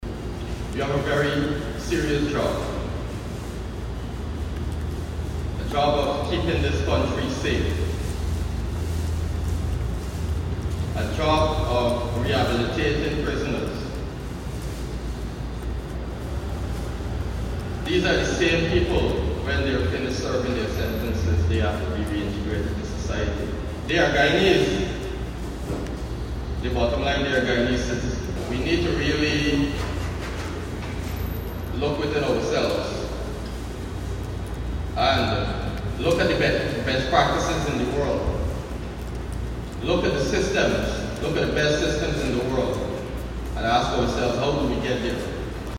In his address to senior functionaries of the Guyana Prison Service, Ally acknowledged the efforts made but also criticized the lapses leading to recent prison escapes.